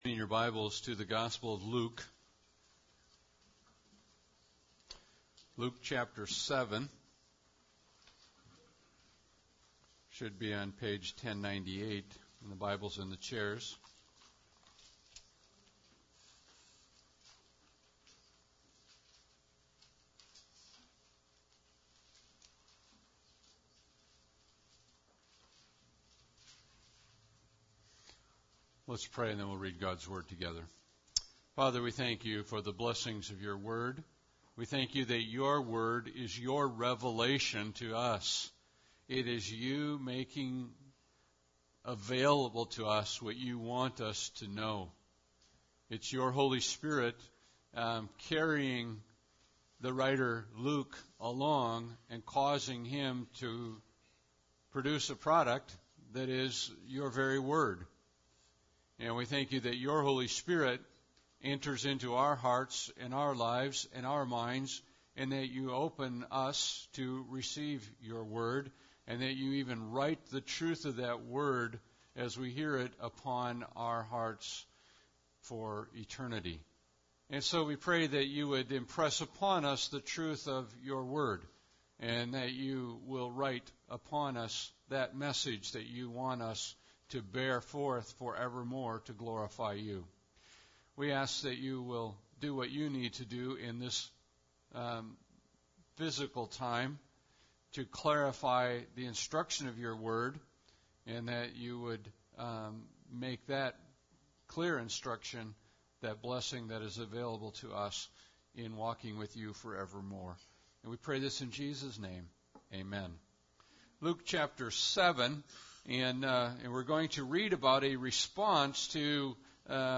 Passage: Luke 7:18-40 Service Type: Sunday Service